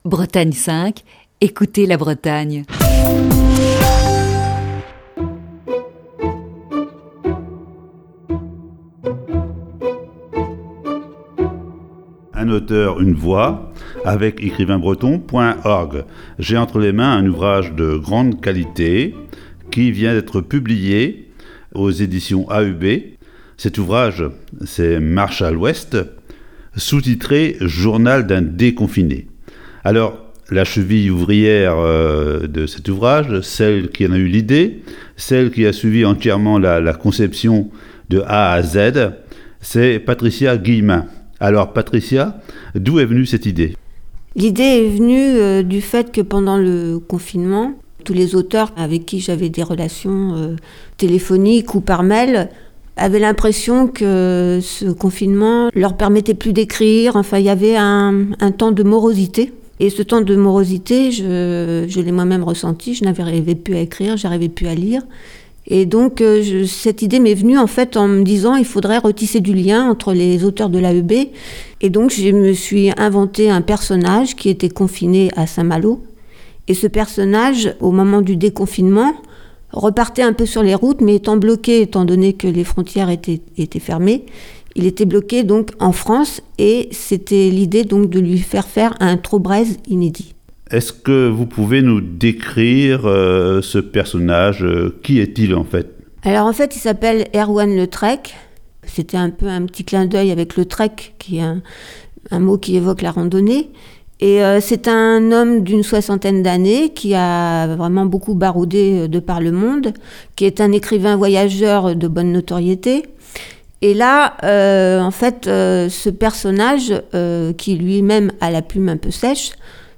Chronique du 22 mars 2021.